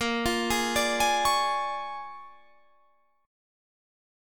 Bb7#9 chord